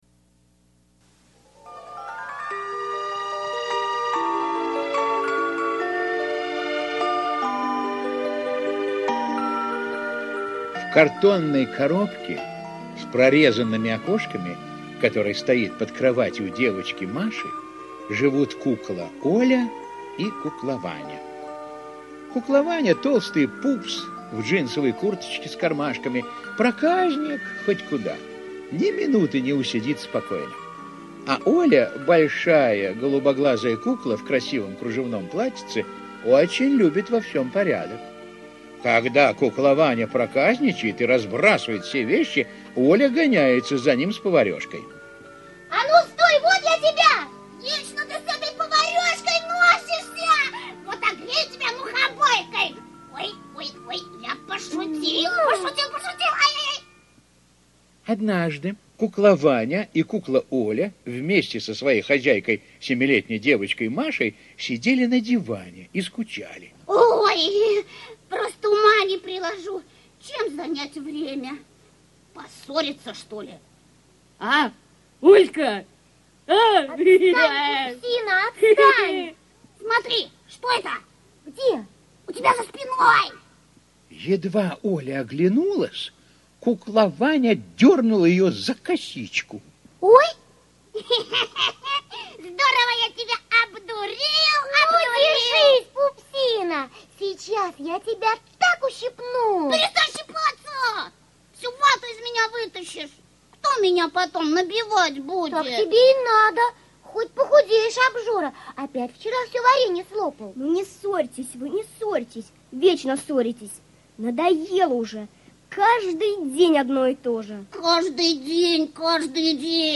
Куклаваня и К - аудиосказка Емеца - слушать онлайн